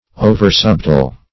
Search Result for " oversubtile" : The Collaborative International Dictionary of English v.0.48: Oversubtile \O"ver*sub"tile\, Oversubtle \O"ver*sub"tle\, a. Excessively subtle.